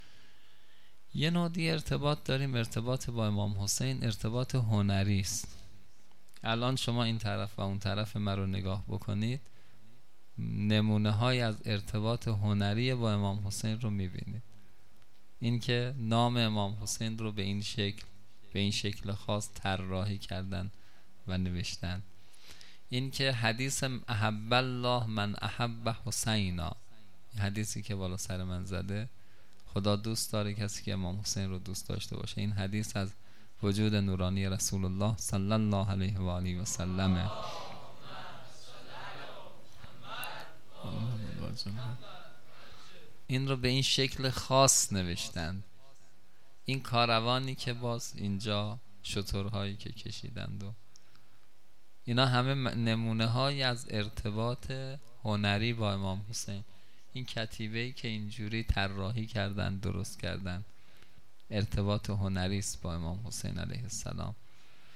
خیمه گاه - هیئت قتیل العبرات - سخنرانی
شب دوم محرم